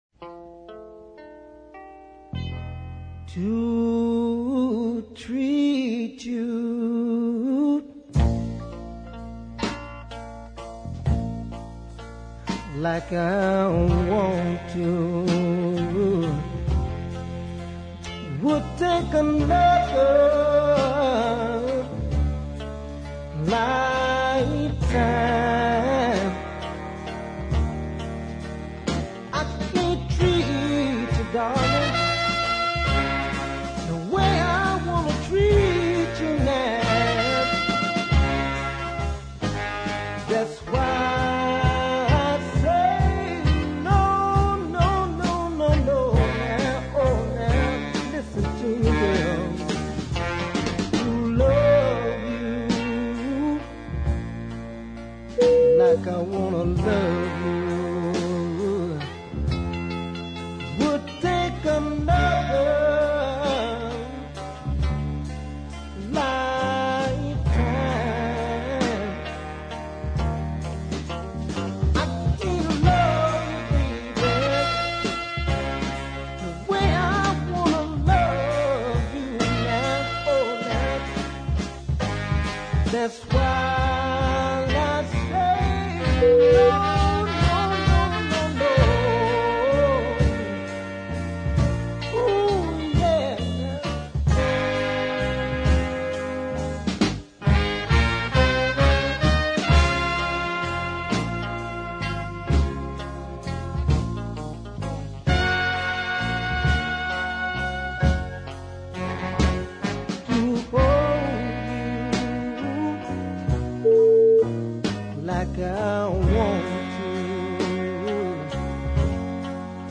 a riveting vocal performance.